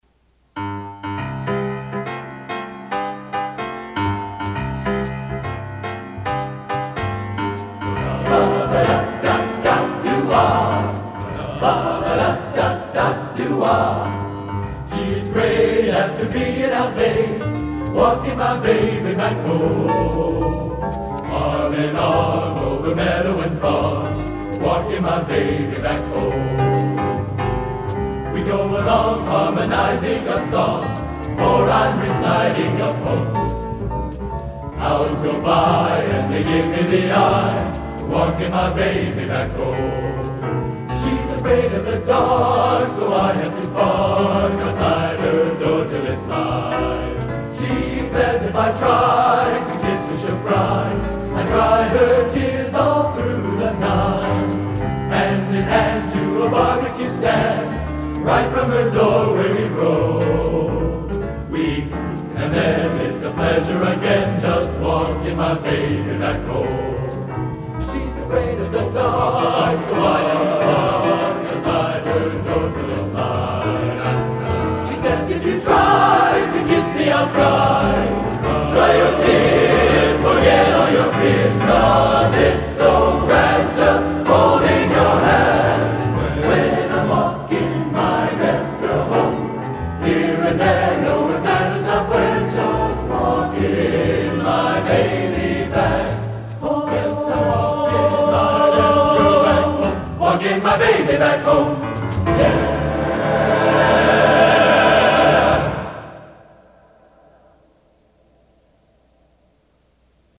Genre: Popular / Standards | Type: